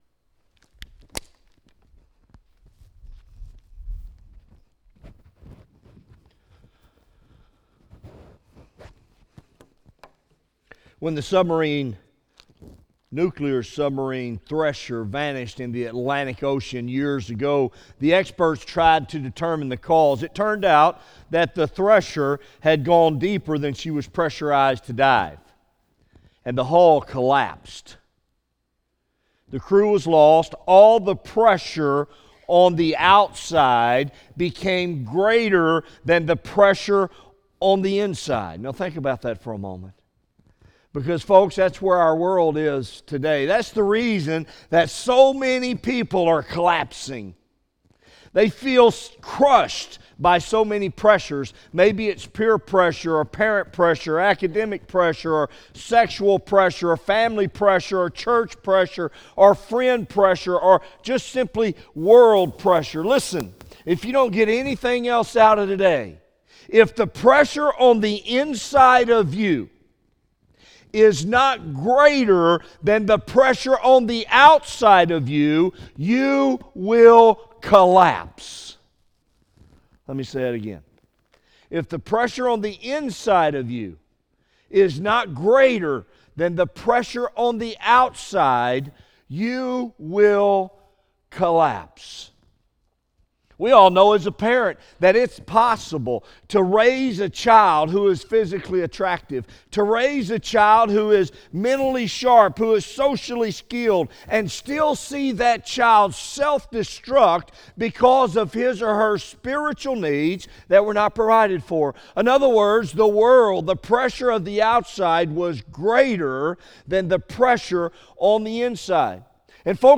Morning Worship
by Office Manager | May 8, 2017 | Bulletin, Sermons | 0 comments